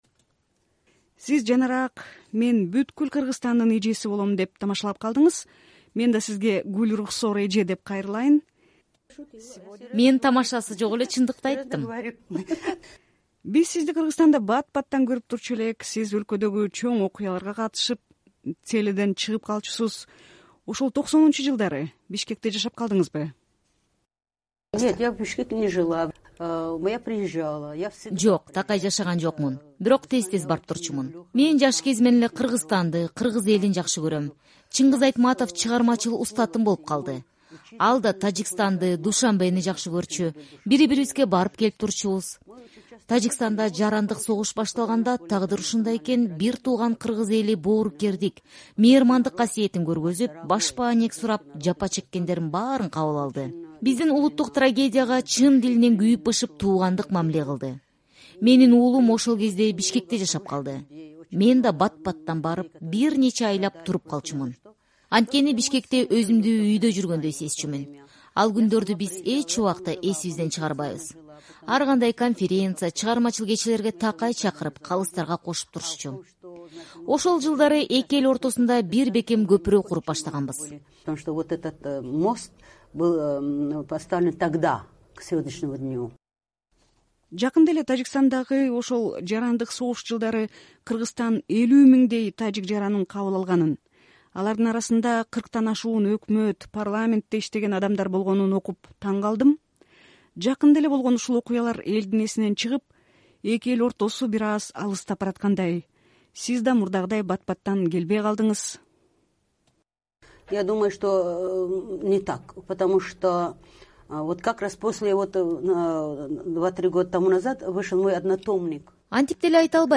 Тажикстандын Эл акыны, СССРдин акыркы Жогорку Кеңешинин депутаты болгон Гүлрухсор Сафиева Кыргызстанда таанымал инсан. Ал 1990-жылдары өзү күбө болгон окуялар, Бишкек менен байланышы тууралуу Прагадагы студияда маек курду.